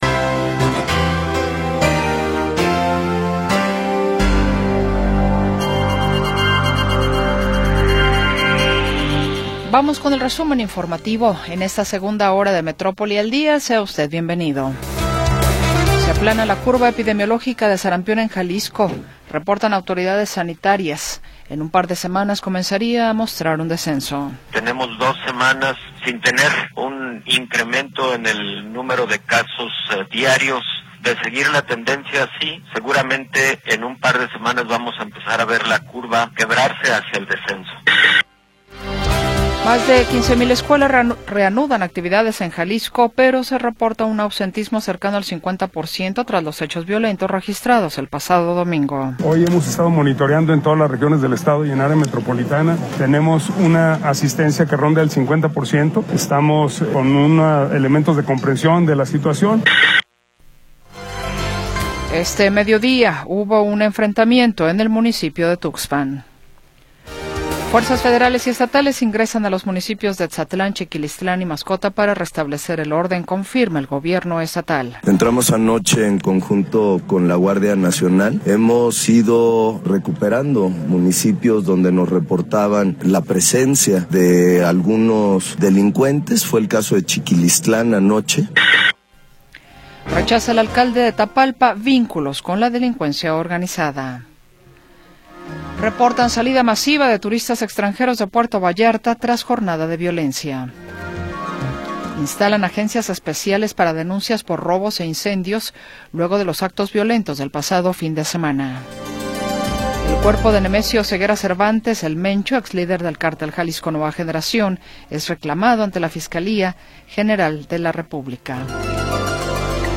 Segunda hora del programa transmitido el 25 de Febrero de 2026.